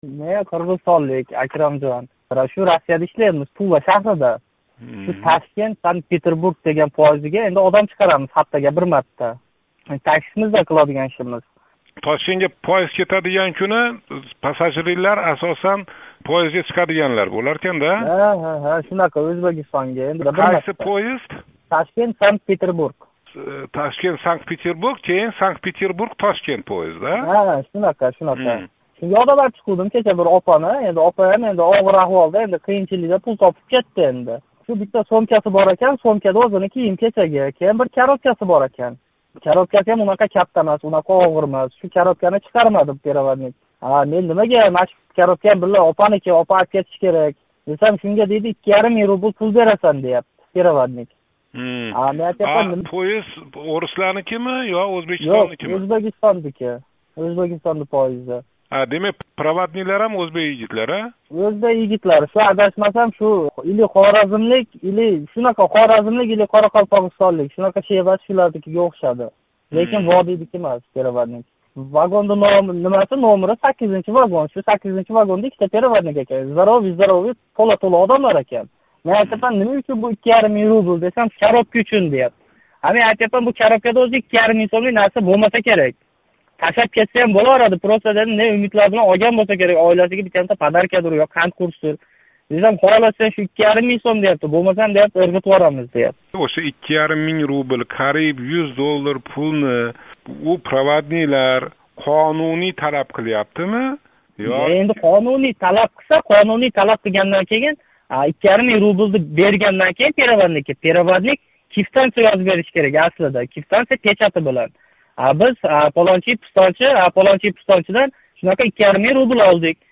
"Эркин микрофон"га бугун қўнғироқ қилганларнинг кўпчилиги Россияда меҳнат қилаётган меҳнат муҳожирлари, президент Ислом Каримов таъбири билан айтганда "одам жирканадиган" "дангасалар" бўлди.